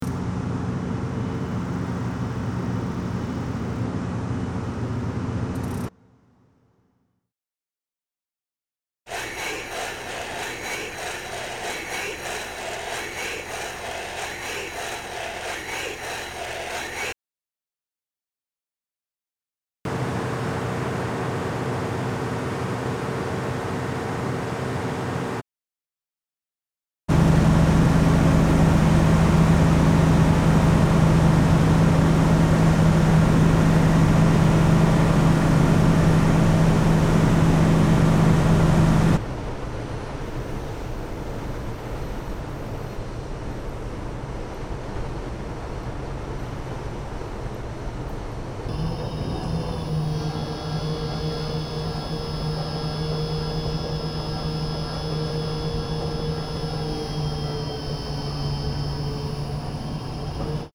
Field Recording Series by Gruenrekorder
It progresses from delightful natural sounds to industrial machines slowly disrupting them.